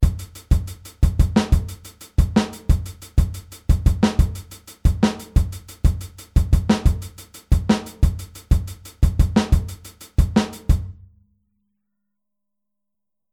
Aufteilung linke und rechte Hand auf HiHat und Snare